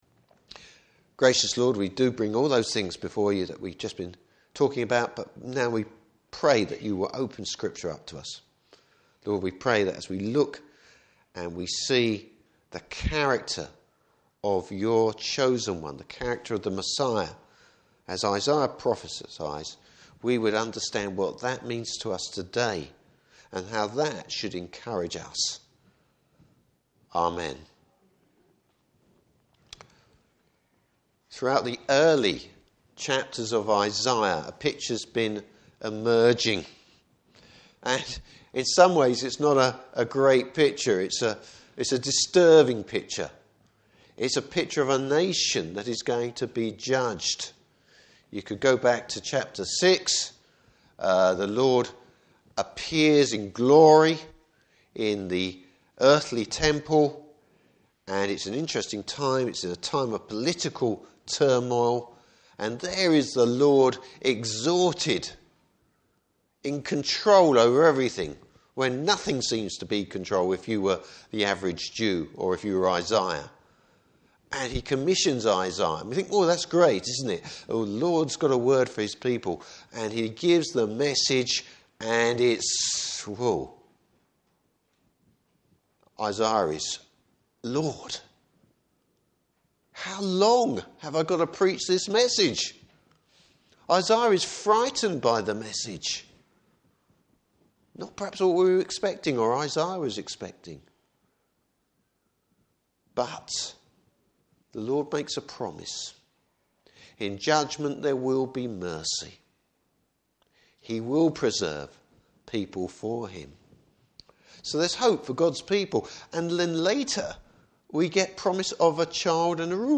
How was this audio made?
Service Type: Morning Service Bible Text: Isaiah 11:1-5.